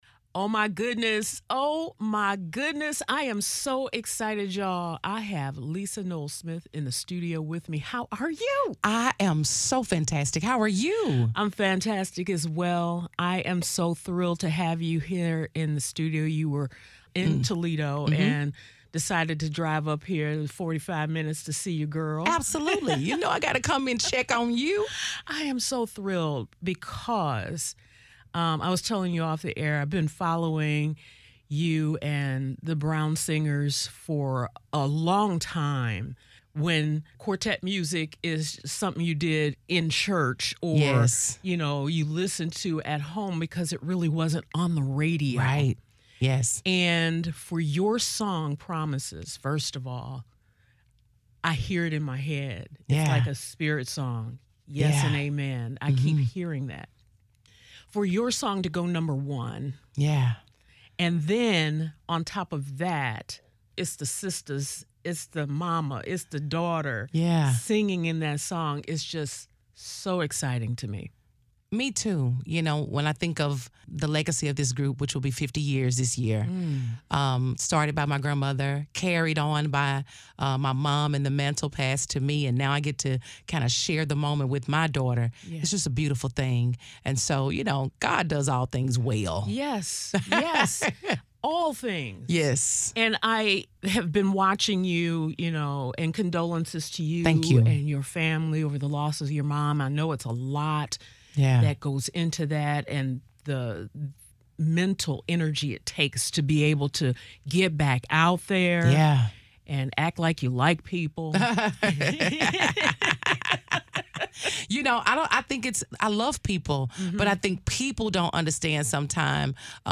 Warm.